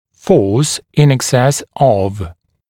[fɔːs ɪn ɪk’ses əv ….. græmz] [ek-][фо:с ин ик’сэс ов ….. грэмз] [эк-]сила более чем в …. гр.